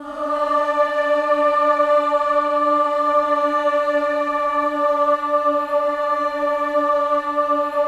VOWEL MV10-R.wav